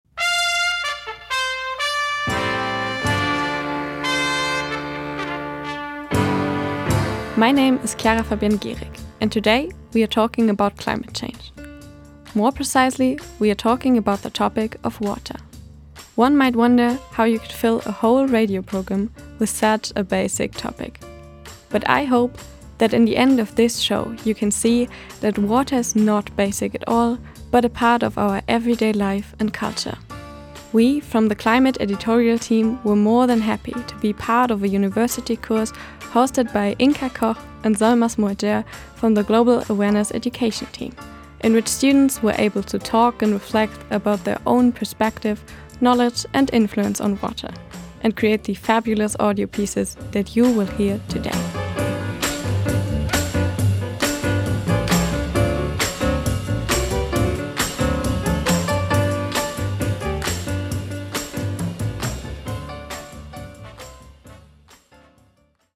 Moderation
Music / Musik: